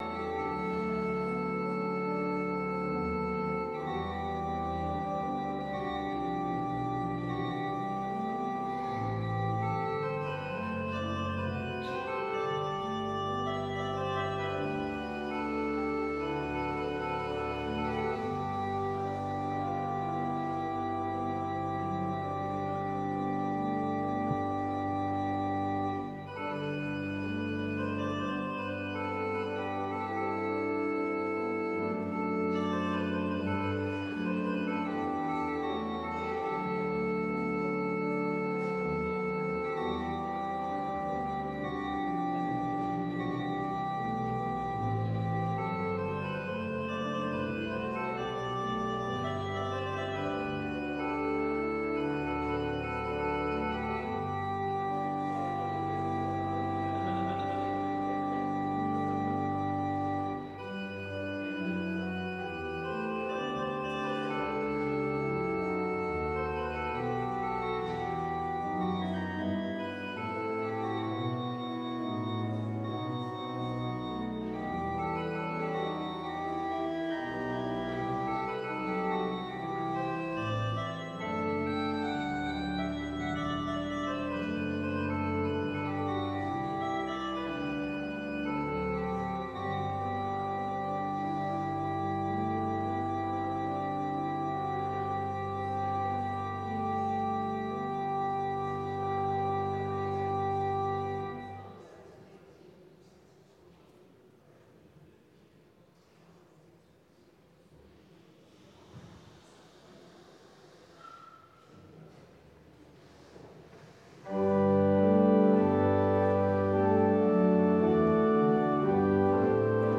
The Scots' Church Melbourne 11am Service 11 December 2022
Full Service Audio